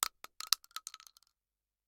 shotgun_generic_7.ogg